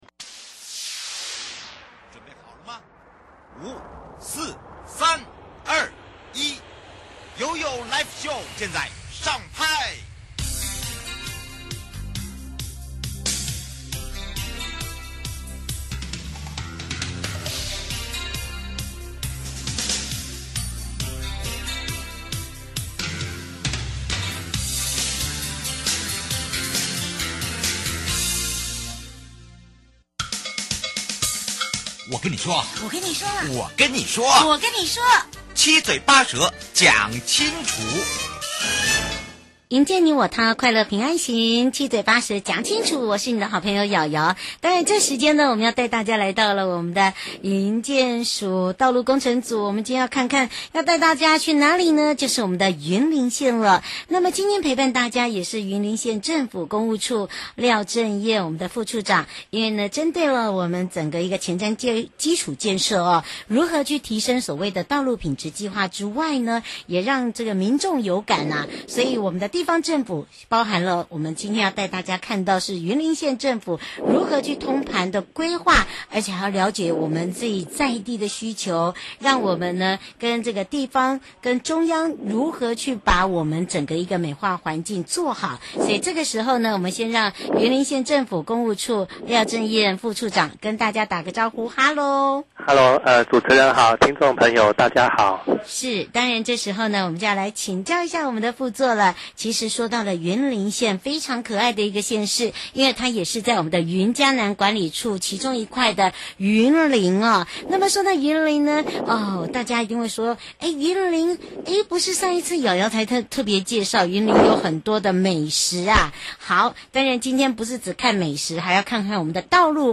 受訪者： 營建你我他 快樂平安行-中央前瞻基礎建設-提升道路品質計畫，雲林縣政府如何通盤性規劃並了解需求，讓中